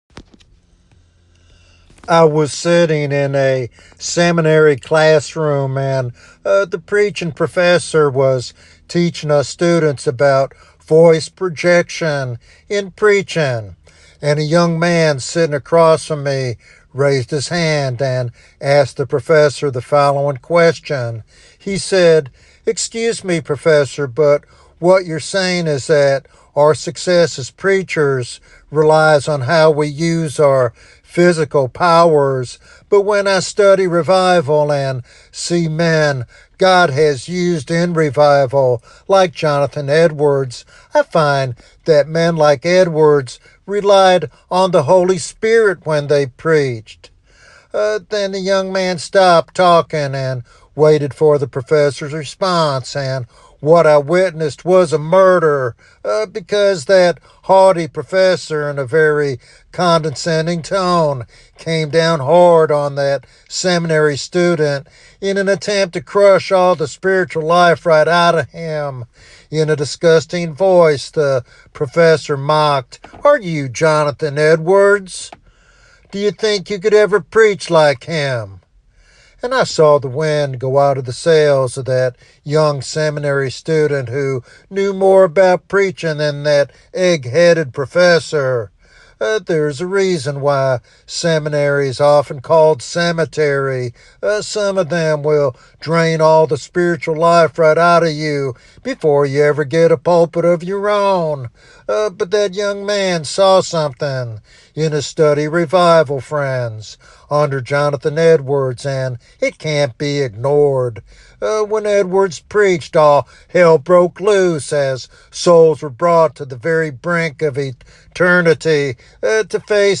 This sermon inspires believers to seek revival by embracing the same faith and courage that marked Edwards' ministry.